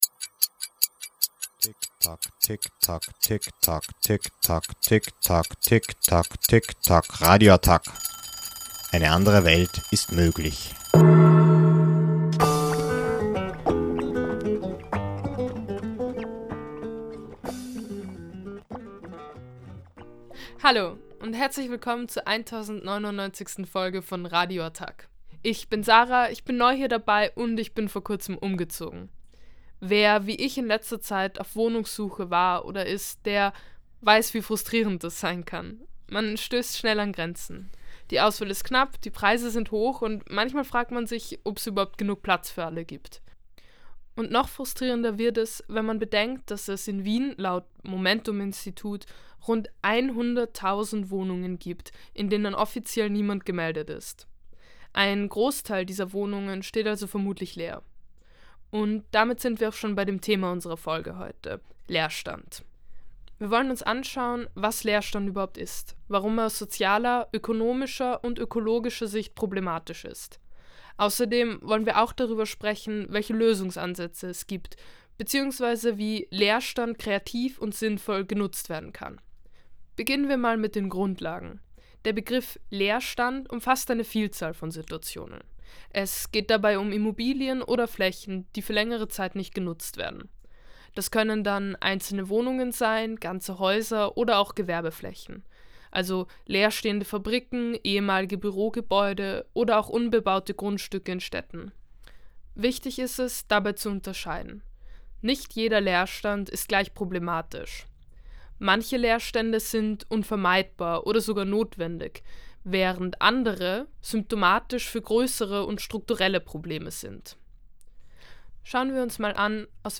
Ein Interview über systematische Missstände in der südeuropäischen Landwirtschaft